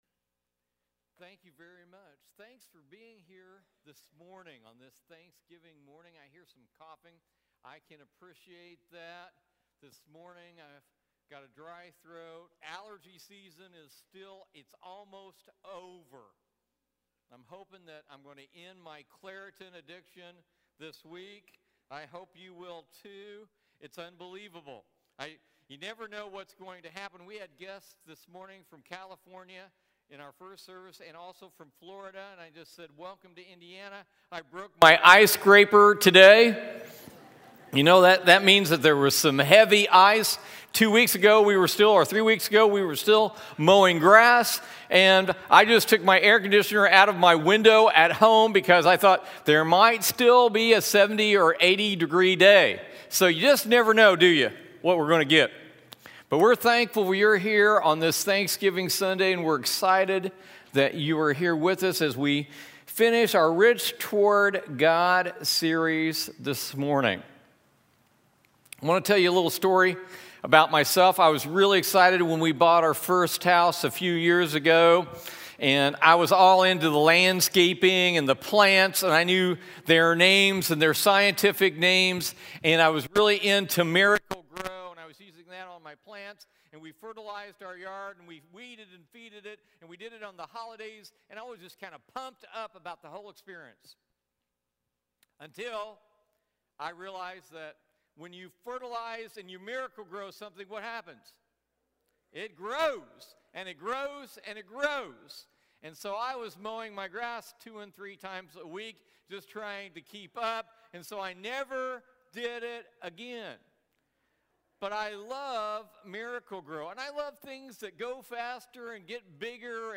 Part 4 of the "Rich Toward God" sermon series.